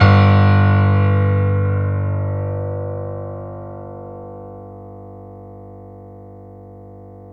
SG1 PNO  G 0.wav